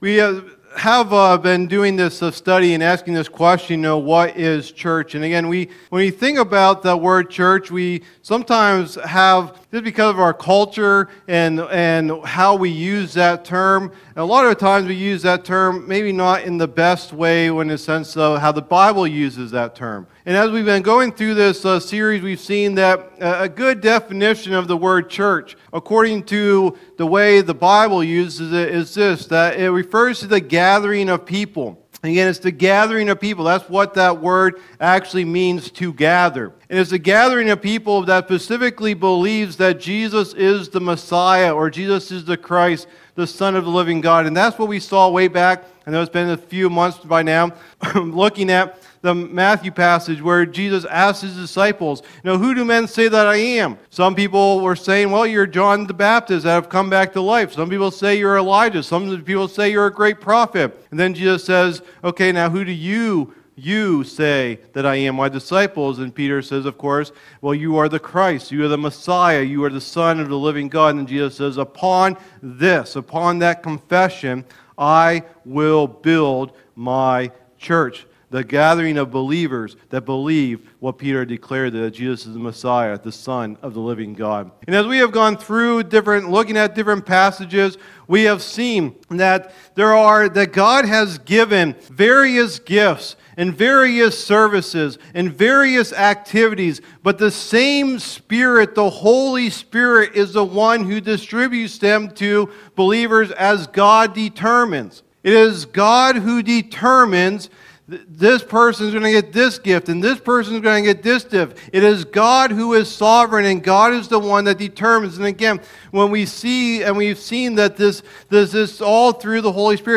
Message #9 of the "What is Church?" teaching series